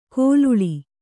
♪ kōluḷi